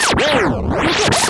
SCRATCHY 2.wav